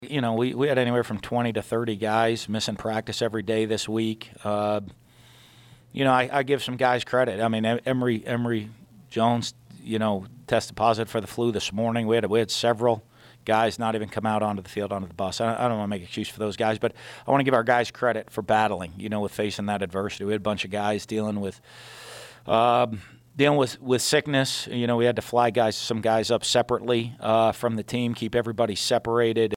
Dan Mullen in the press conference